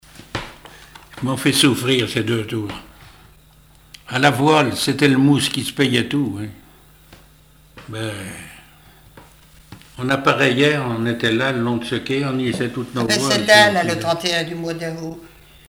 Chansons et témoignages maritimes
Catégorie Témoignage